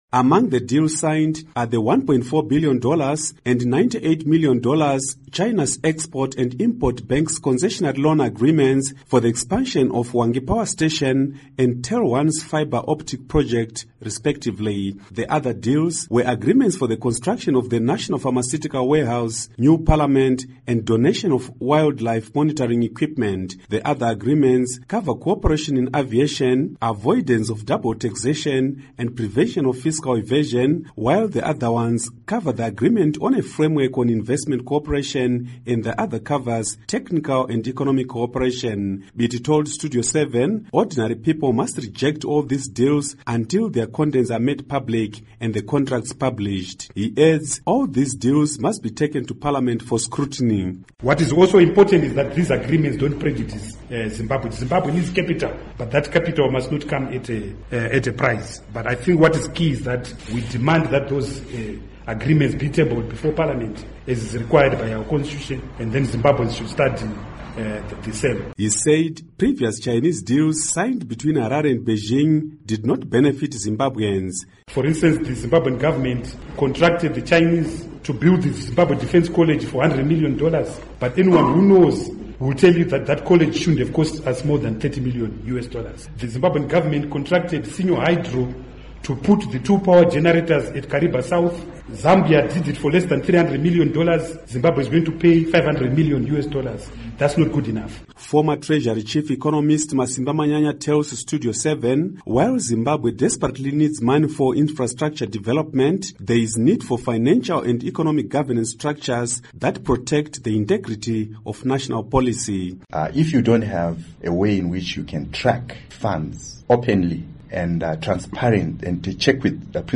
Report on Chinese Deals